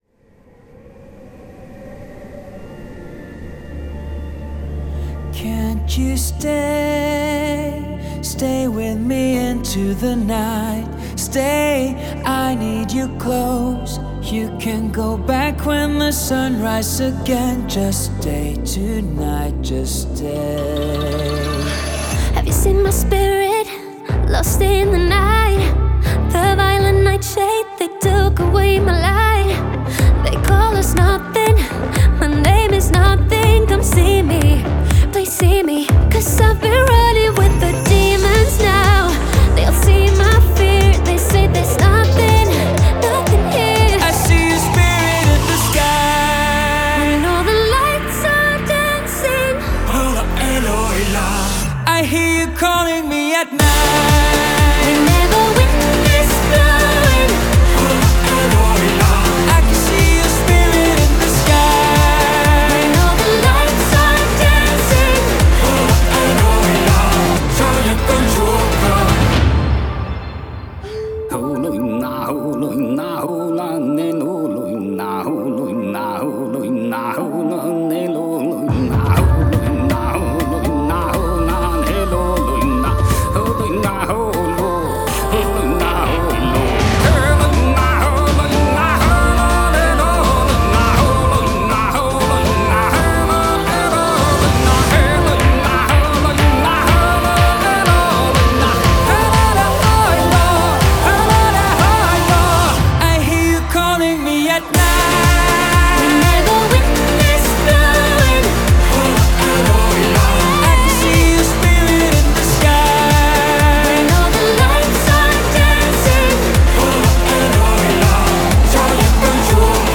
BPM122
Audio QualityCut From Video